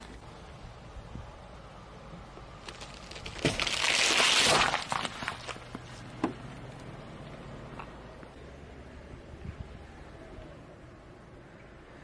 crushing crunchy Soft things by sound effects free download